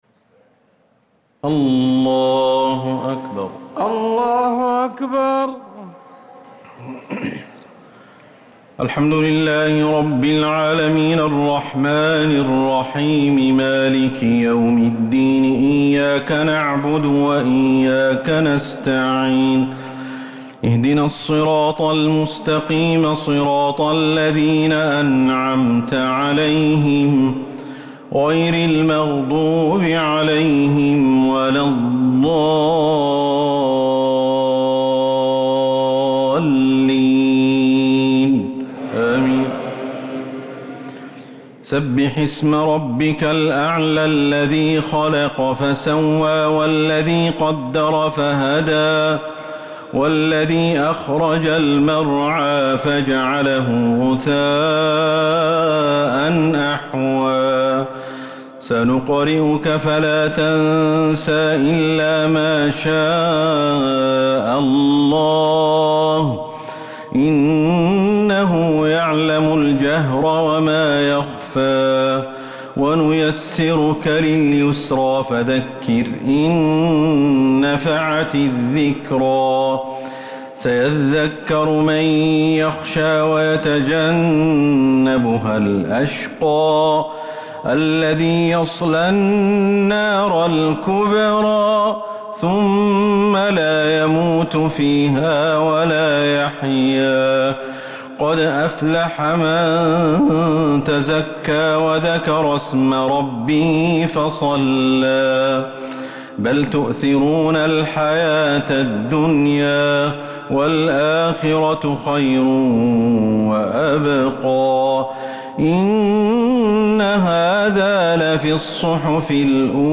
صلاة الشفع و الوتر ليلة 1 رمضان 1444هـ | Witr 1st night Ramadan 1444H > تراويح الحرم النبوي عام 1444 🕌 > التراويح - تلاوات الحرمين